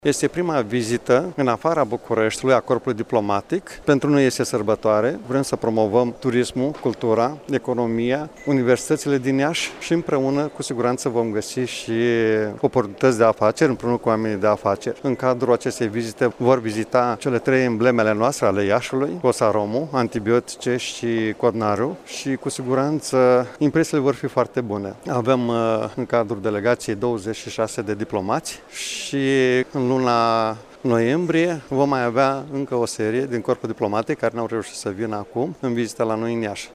Aceştia vor vizita societăţile reprezentative pentru industria ieşeană cum ar fi Cotnari, Antibiotice şi Kosarom şi vor avea loc dialoguri cu reprezenanţi ai mediului academic – a precizat preşedintele Consiliului Judeţean Iaşi, Maricel Popa: